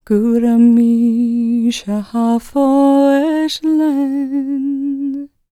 L  MOURN A01.wav